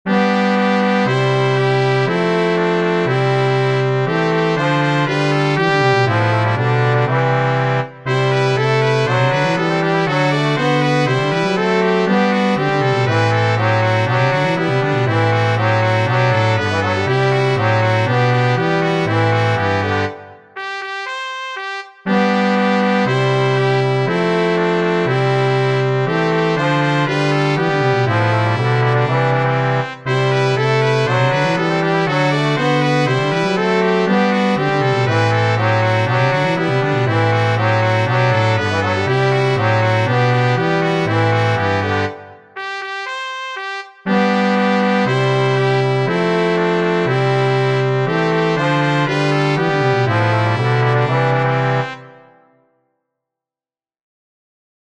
[MP3 Brass quartet]